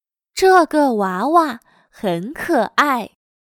这个娃娃很可爱。/zhège wáwa hěn kě′ài./Esta muñeca es tan linda.